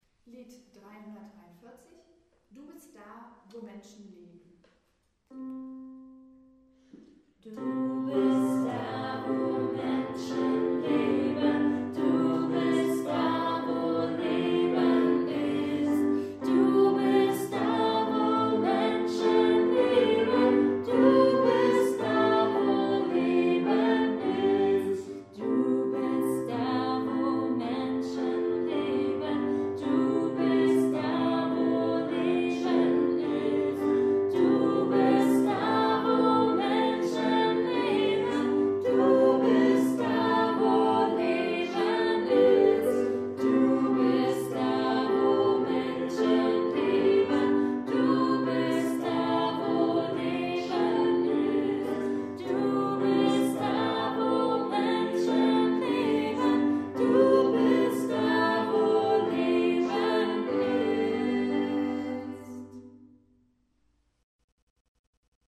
Lied für zu Hause | Christliche Liederdatenbank
du_bist_da_wo_menschen_leben_kanon.mp3